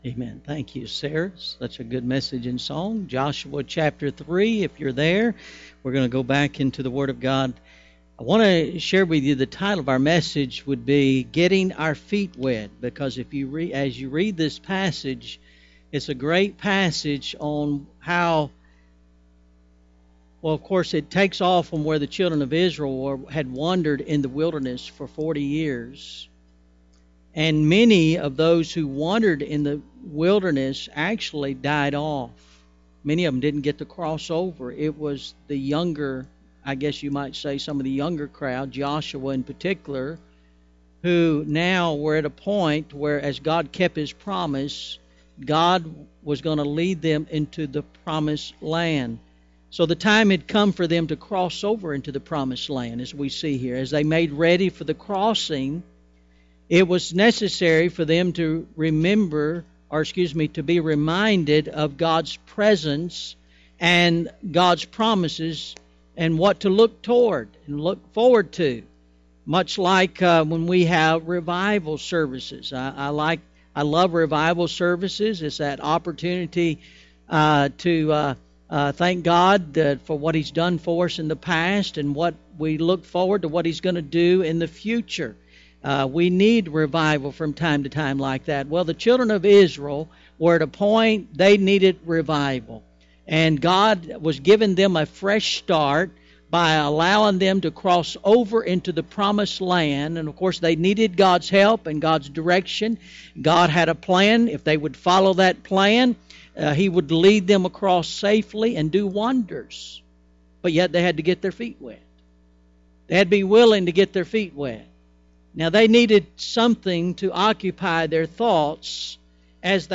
Getting our Feet Wet – October 12th, 2014 – AM Service
The Word was verified (v. 14-17) Getting our Feet Wet – Audio (MP3) Filed Under: Sermons , SundayAM